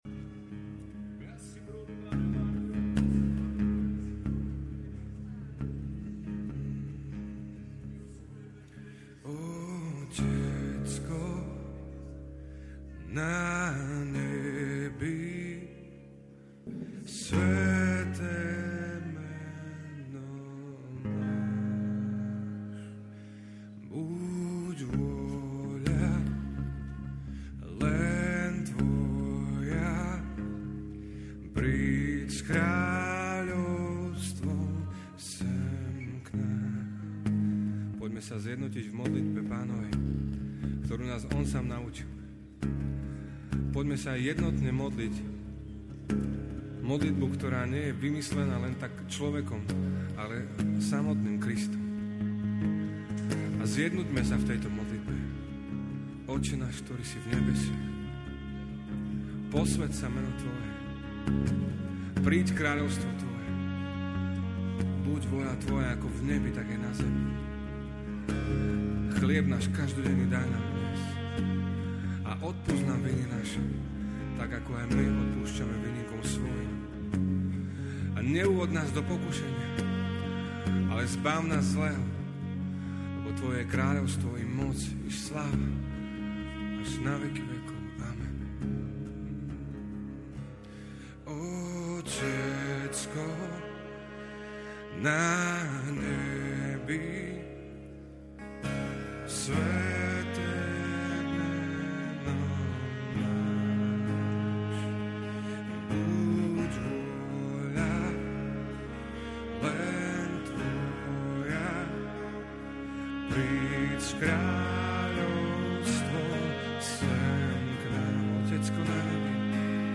Ranné chvály (gitara&husle